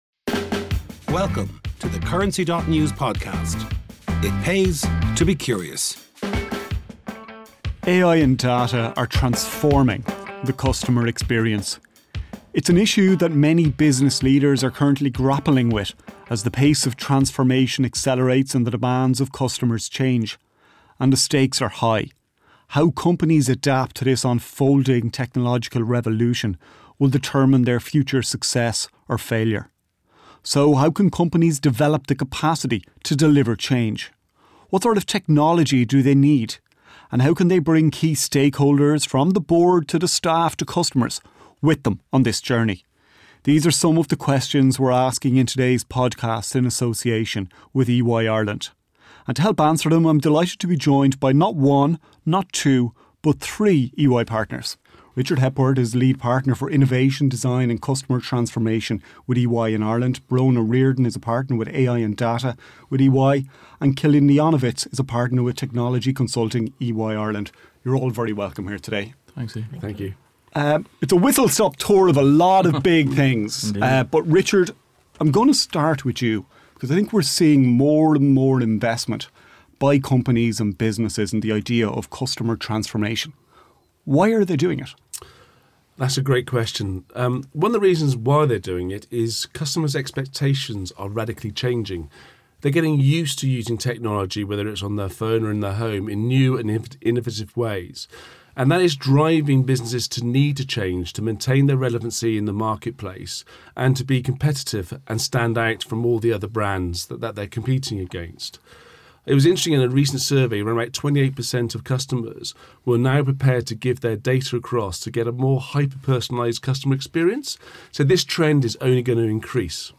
Stories Knowing the customer: Harnessing AI and data across the customer lifecycle AI and data are reshaping customer experience, forcing businesses to adapt fast. In this podcast with EY Ireland, three EY partners explore how companies can leverage technology, data, and strategy to stay ahead in a rapidly evolving market. 16th Apr, 2025 - 4 min read Listen Now